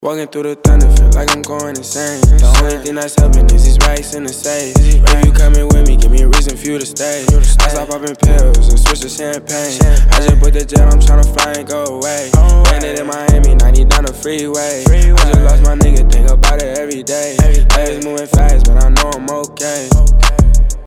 • Качество: 320, Stereo
мужской голос
лирика
Хип-хоп
грустные
спокойные